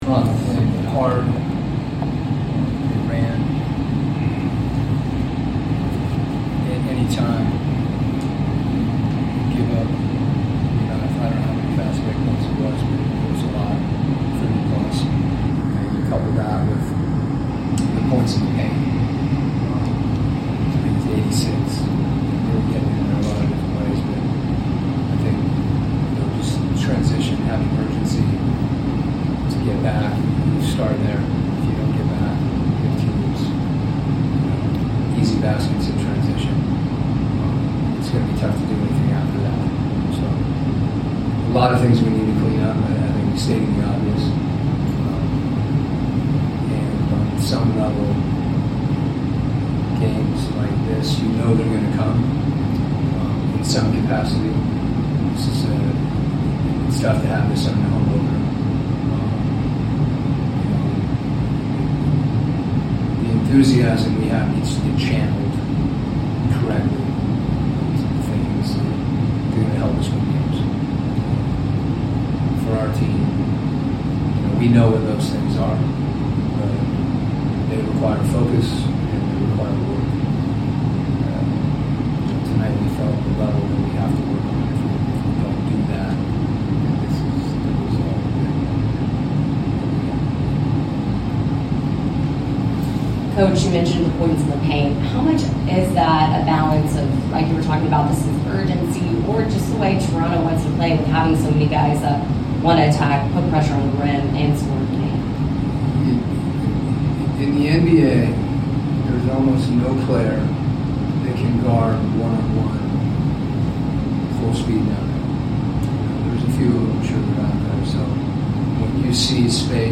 10-22-25 Atlanta Hawks Coach Quin Snyder Postgame Interview
Atlanta Hawks Coach Quin Snyder Postgame Interview after losing to the Toronto Raptors at State Farm Arena.